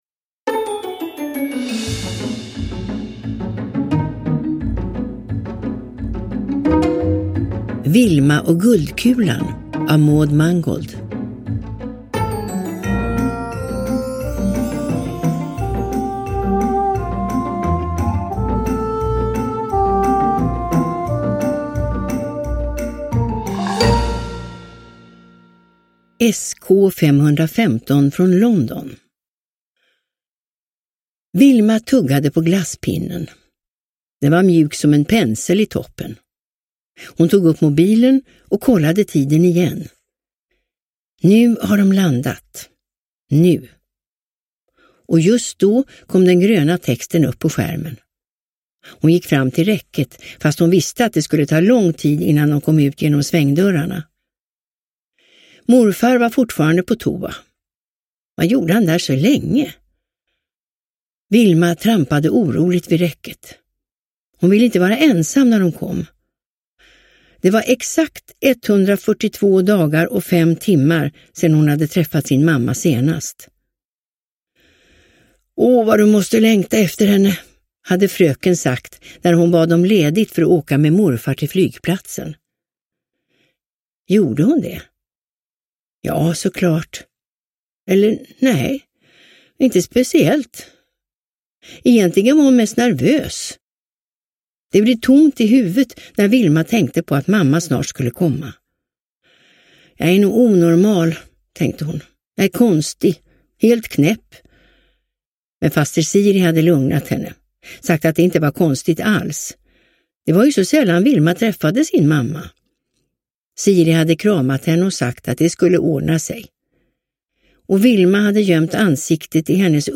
Vilma och guldkulan – Ljudbok